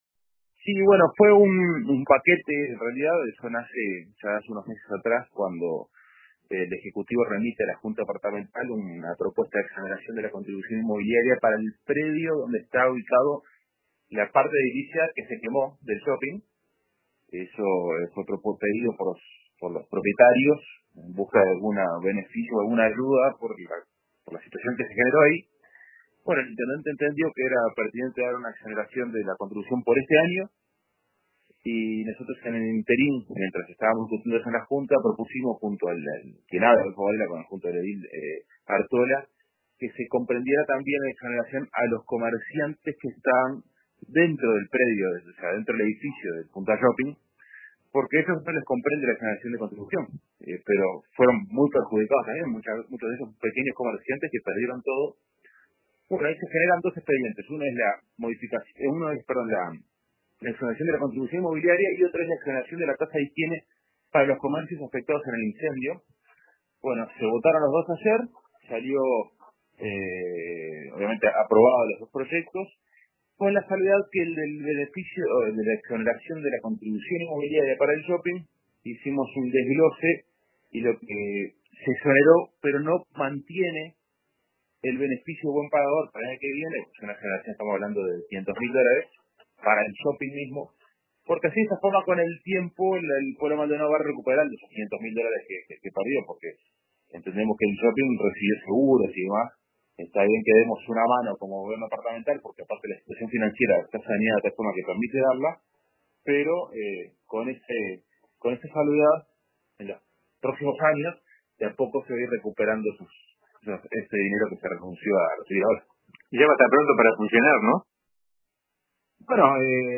El edil nacionalista Adolfo Varela contó a RADIO RBC cómo se dio este trámite y lo votado anoche.